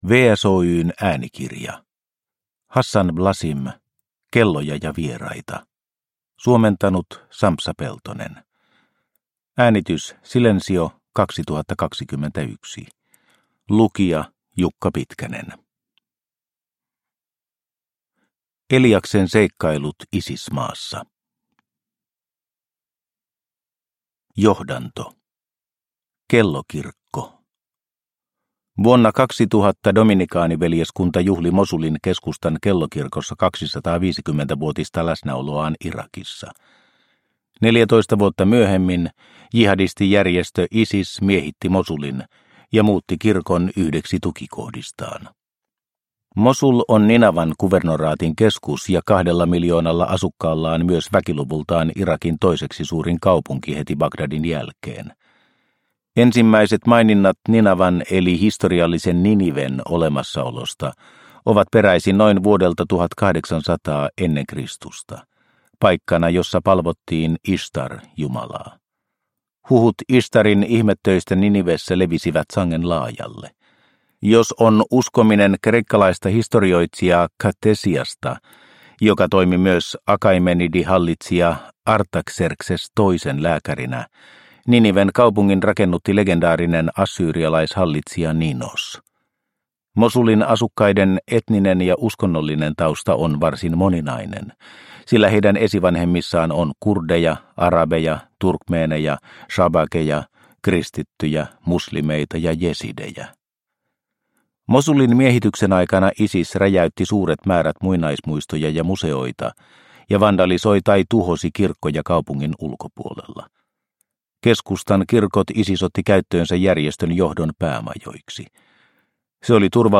Kelloja ja vieraita – Ljudbok – Laddas ner